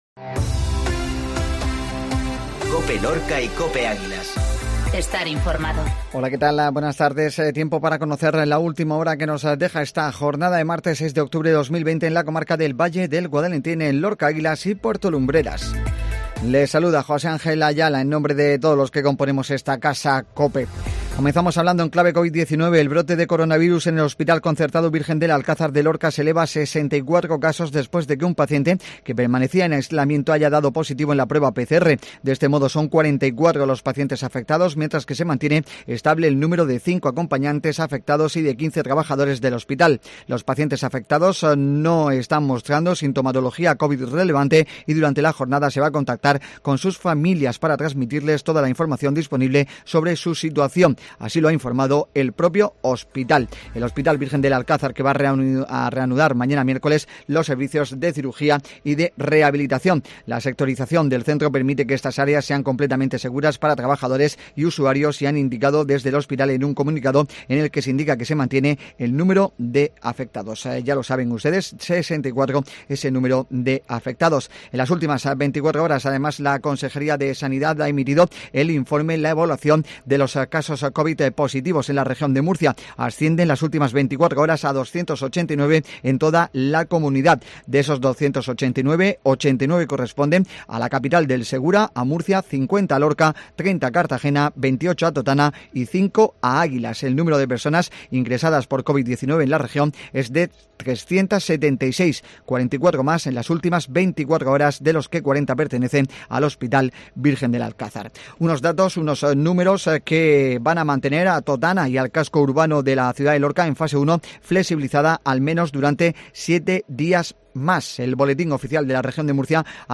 INFORMATIVO MEDIODÍA MARTES